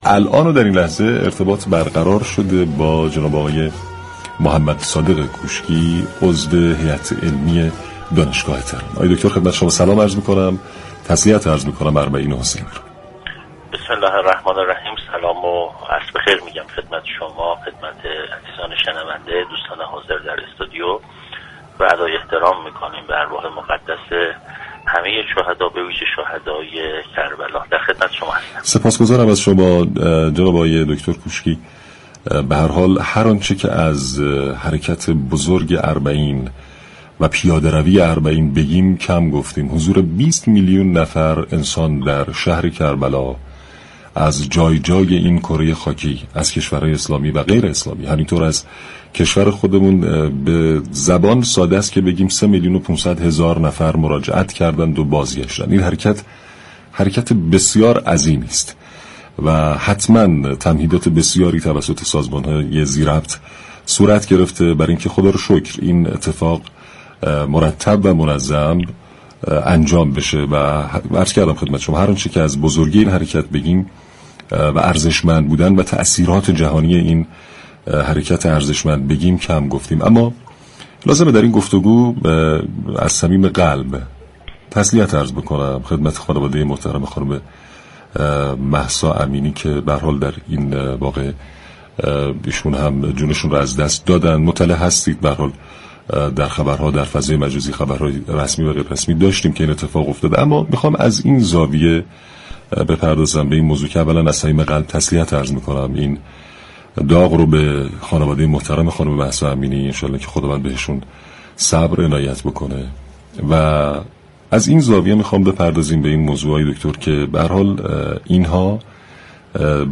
كارشناس سیاسی